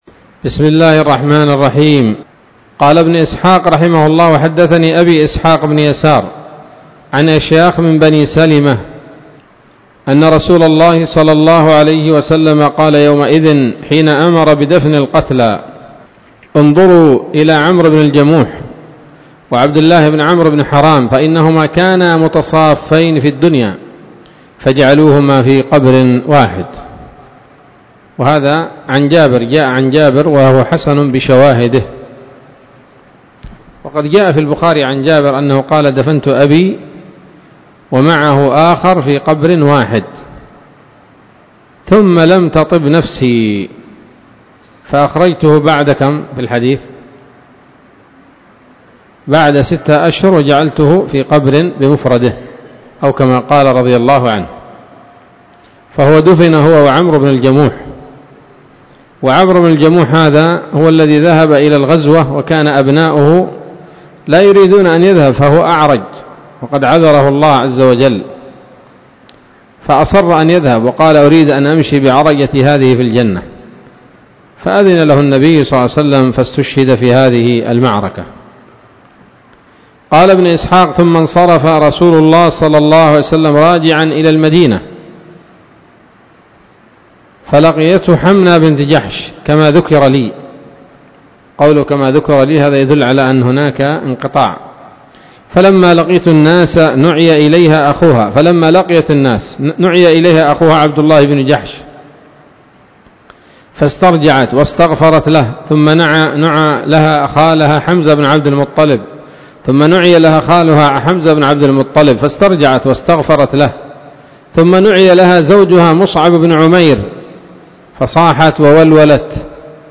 الدرس السابع والستون بعد المائة من التعليق على كتاب السيرة النبوية لابن هشام